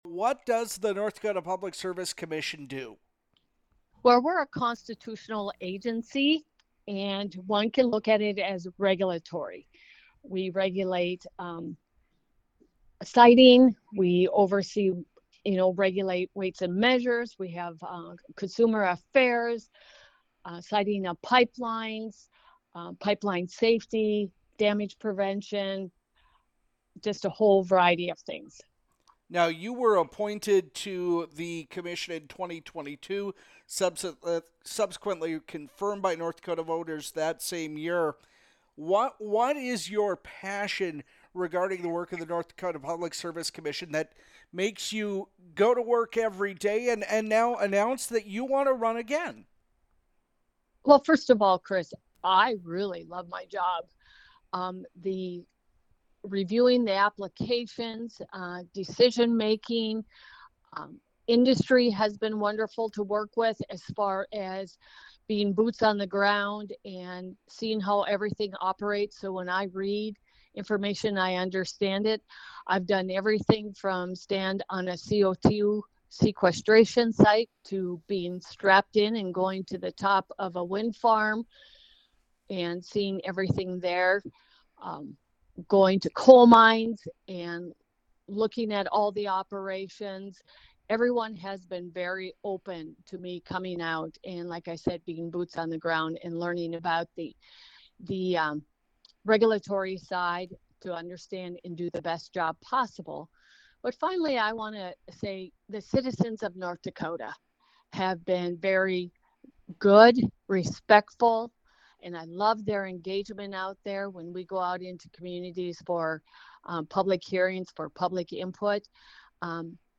Sheri Haugen-Hoffart’s interview